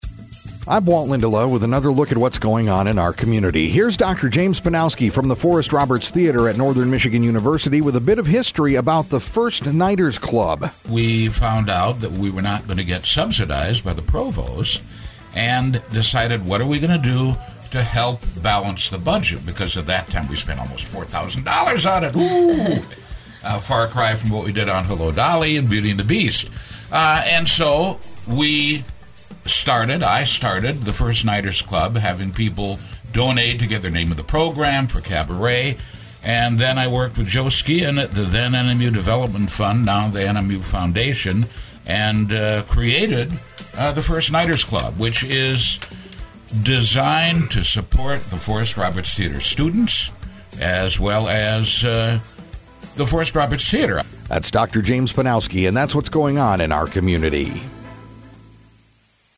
Click HERE To Listen To Interview as Mp3 Click To Submit Press Releases, News, Calendar Items, and Community Events to mediaBrew radio stations WFXD, WKQS, WRUP, GTO,...